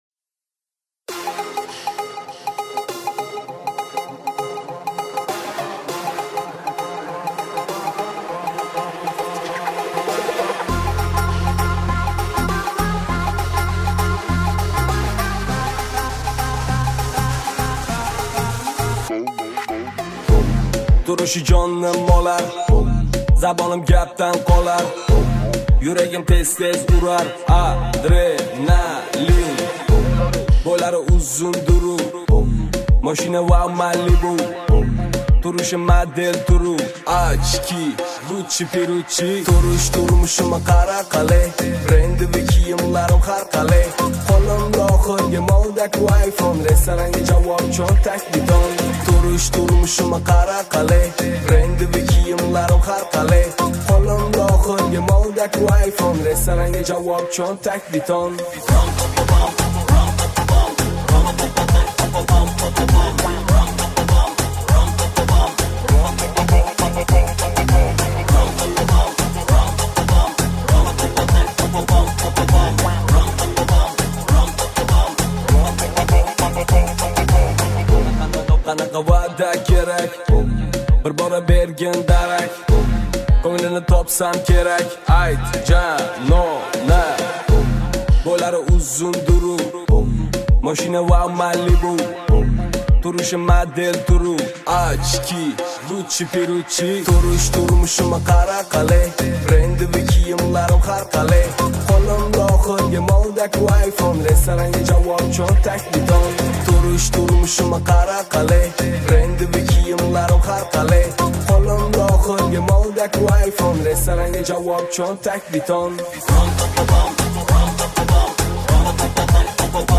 • Категория: Узбекские песни